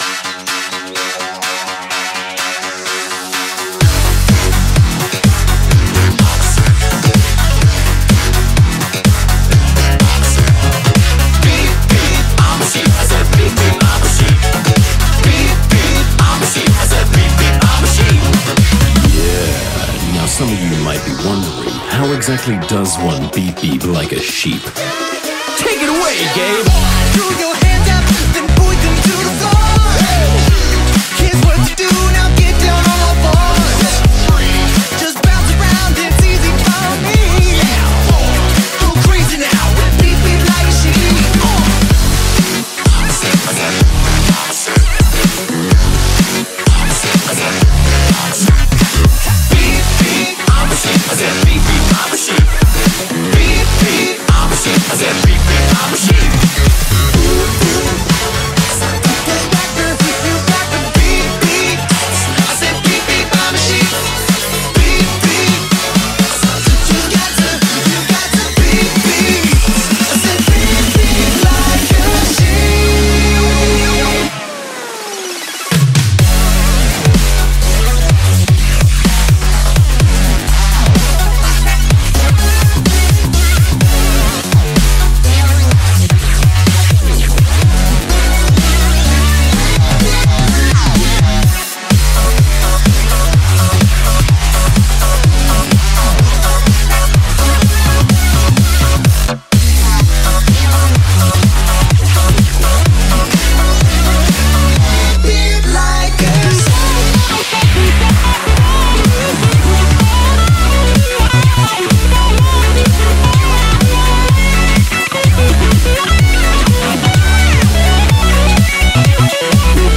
BPM126